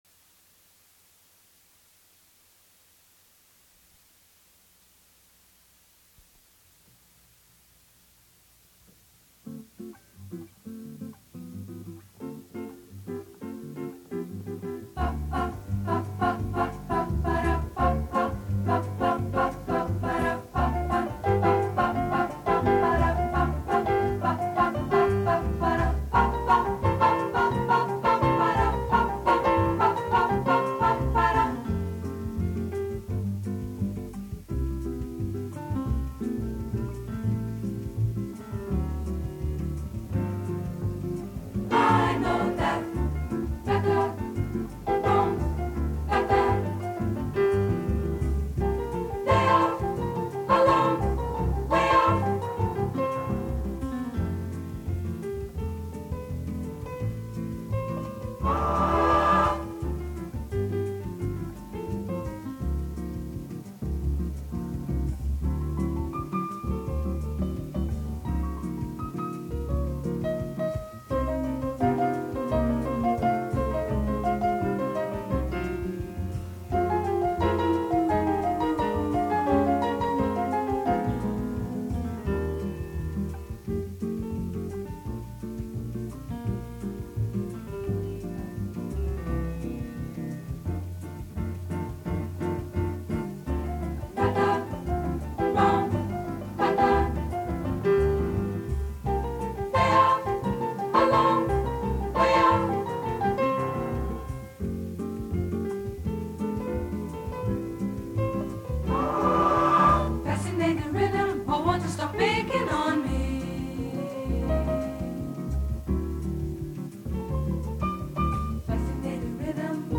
com piano, violão, contrabaixo, bateria e vocais.
Fita de estúdio.
Type: Gravação musical